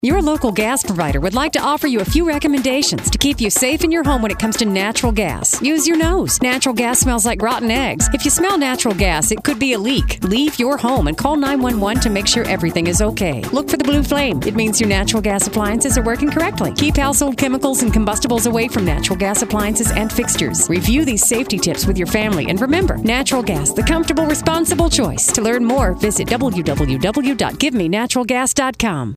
Municipal Gas Radio Ad
Female Announcer voice with upbeat read